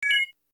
search_updated.ogg